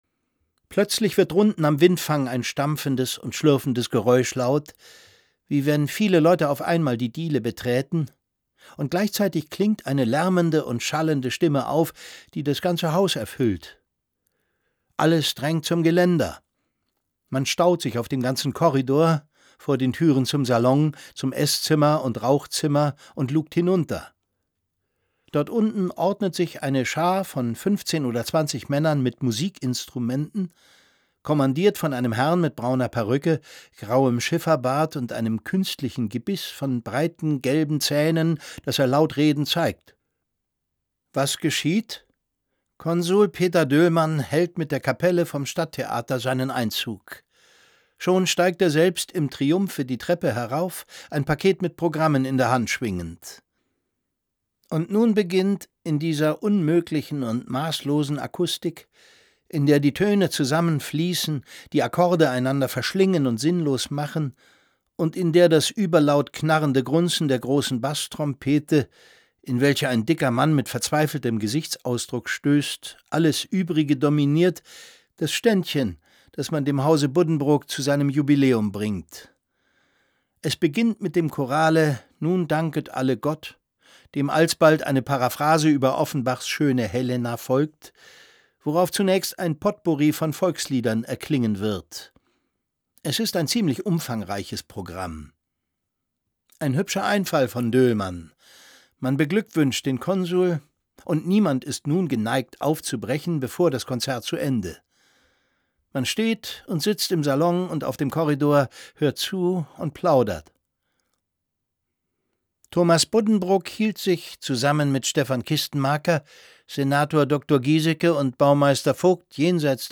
Thomas Mann: Buddenbrooks (46/71) ~ Lesungen Podcast